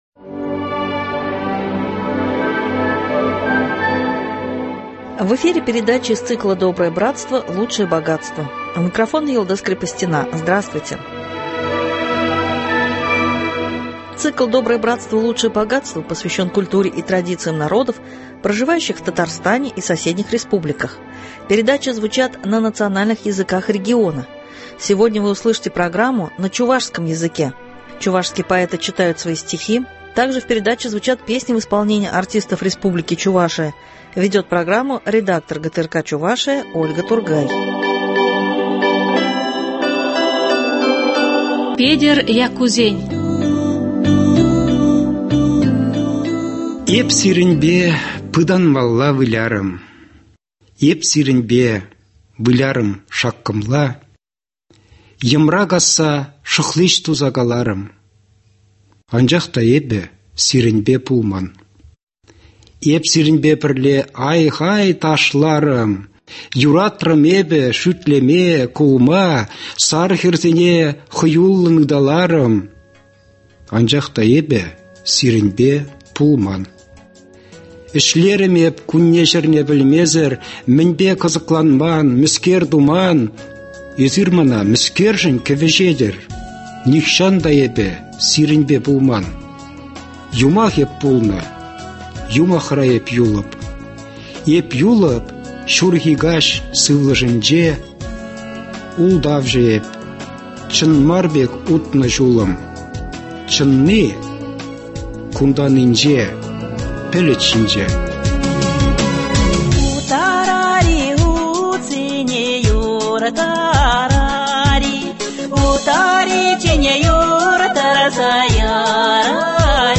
звучат стихи чувашских писателей.